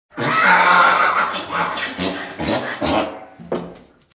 جلوه های صوتی
دانلود صدای خر عصبانی از ساعد نیوز با لینک مستقیم و کیفیت بالا